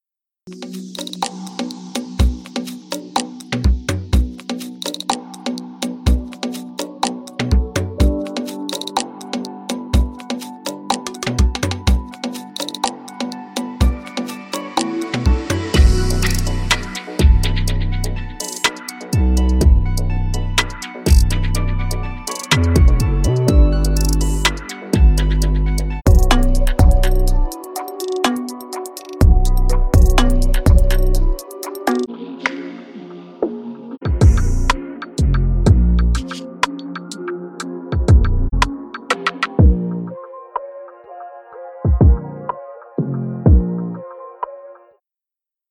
对于我们全新的滴灌套件的第三卷：50美元花掉Vol.3，我们选择了当地的一美元商店作为我们的位置。